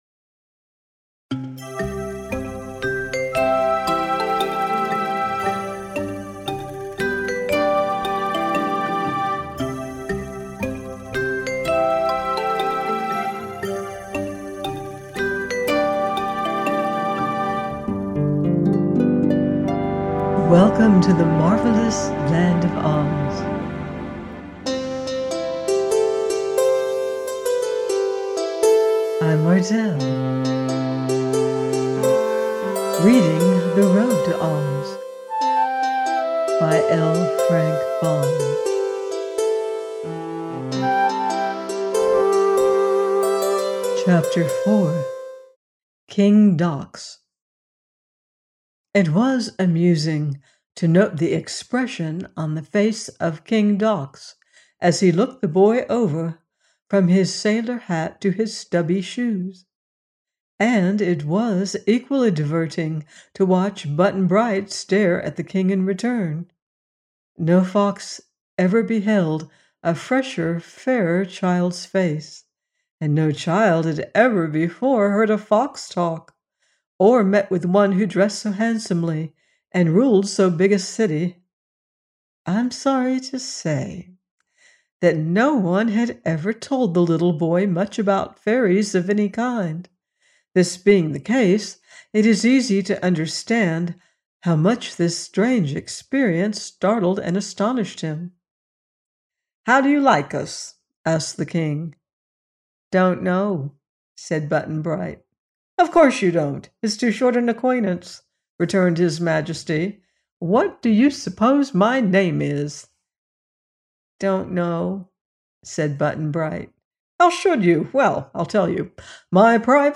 The Road To OZ – by L Frank Baum - audiobook